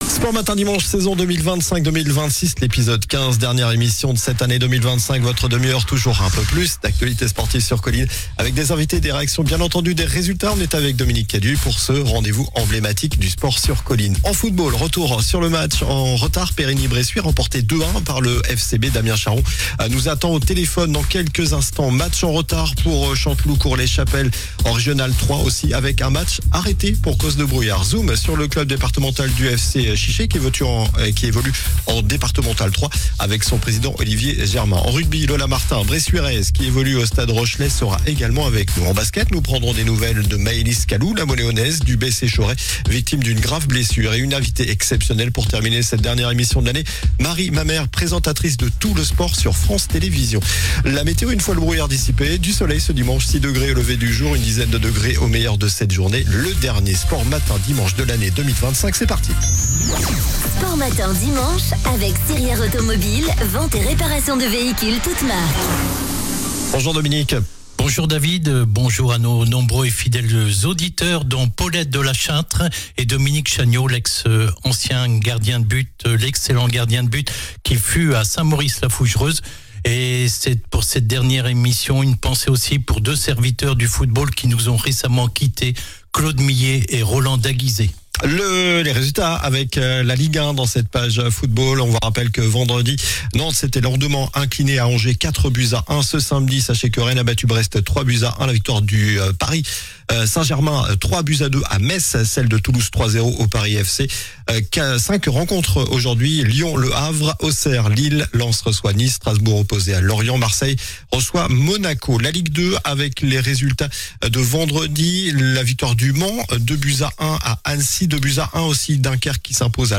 sports invités résultats